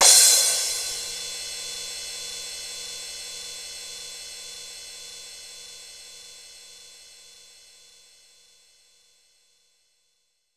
• Cymbal Sound Sample F Key 04.wav
Royality free cymbal sound clip tuned to the F note. Loudest frequency: 6569Hz
cymbal-sound-sample-f-key-04-u45.wav